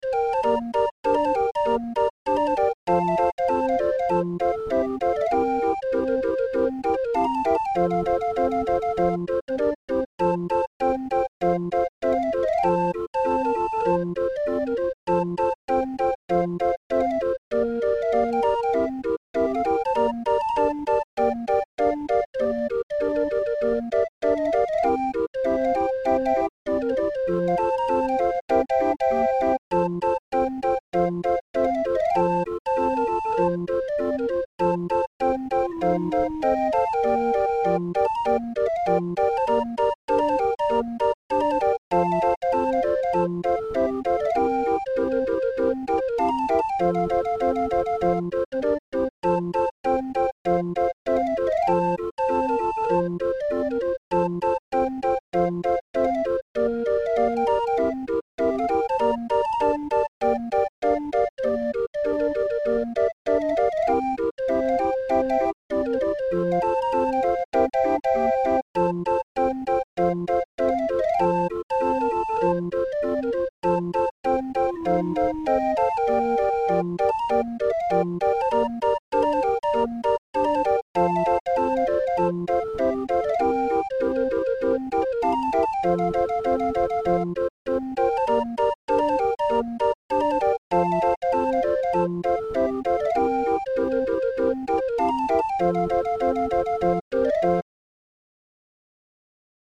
Musikrolle für 20-er Raffin